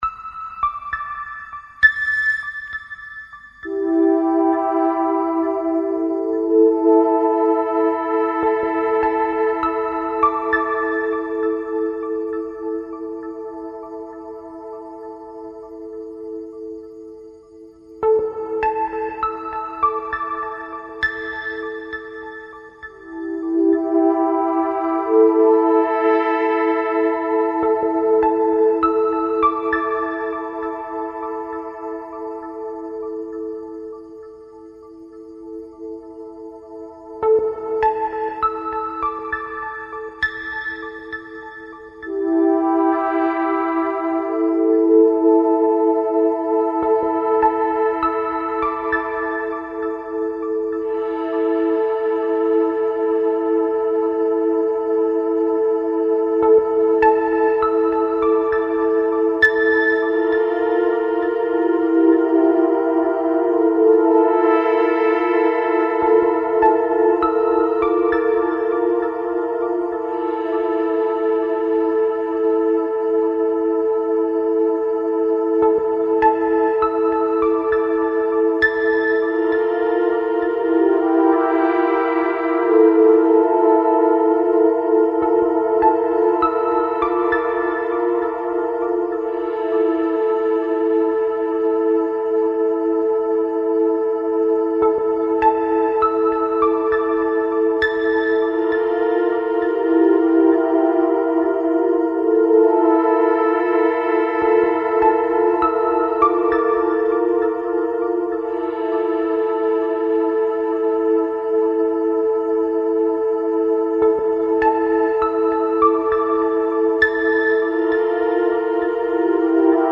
File under: Avantgarde
pure electronic delights